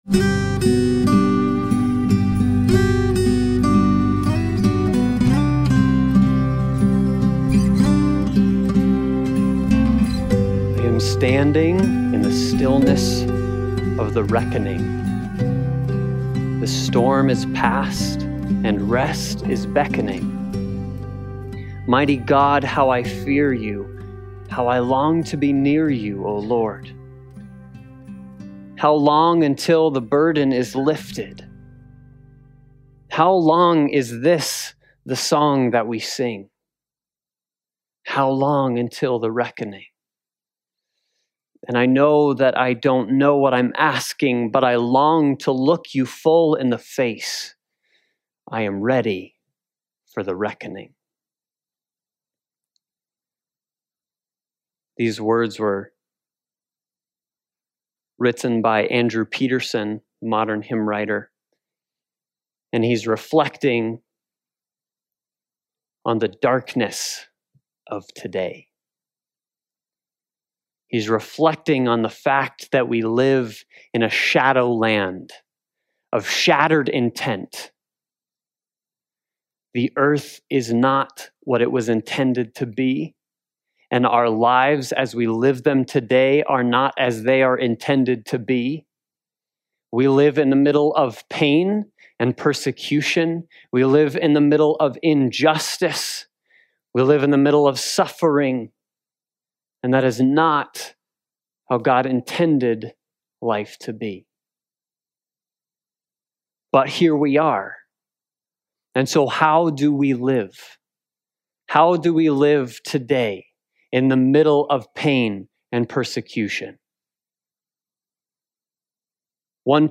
James 5:7-12 Service Type: Sunday Morning Worship Topics